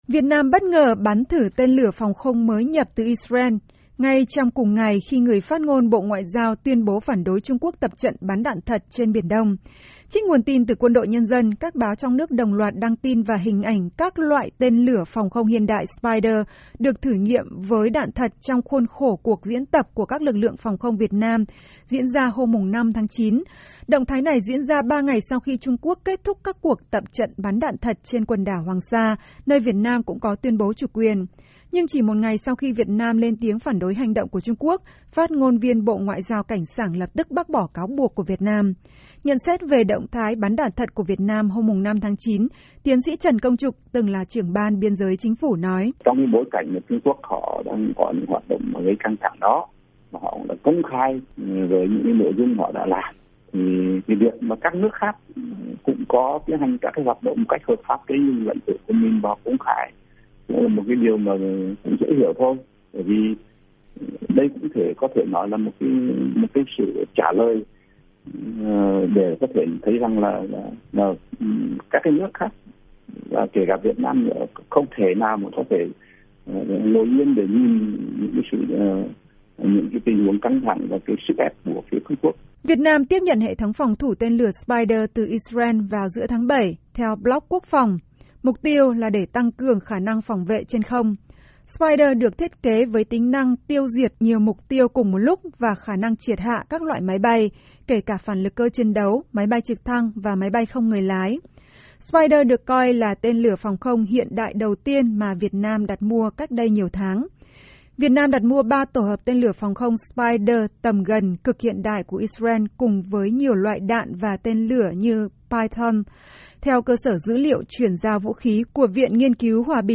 Việt Nam bắn thử tên lửa sau khi Trung Quốc tập trận trên biển Đông. Phim tài liệu Chiến tranh Việt Nam- Phỏng vấn Đạo diễn Lynn Novick.